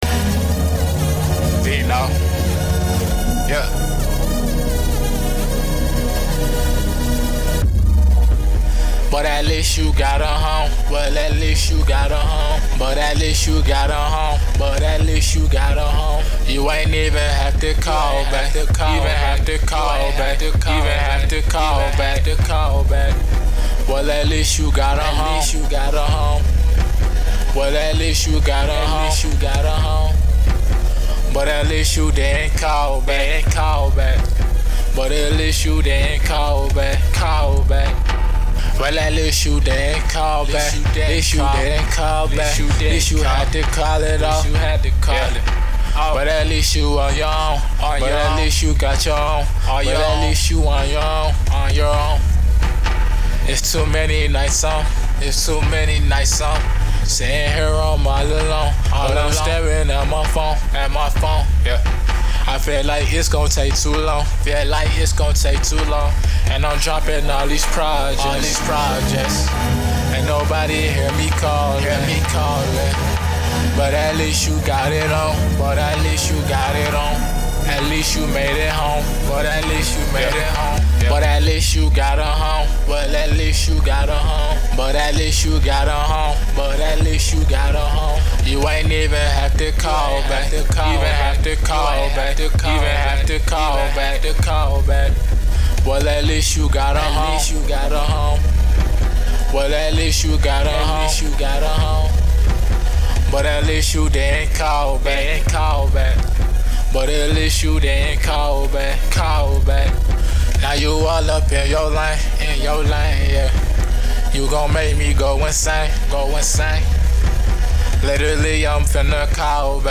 R&B, HipHop, Trap, & Melodic Poject!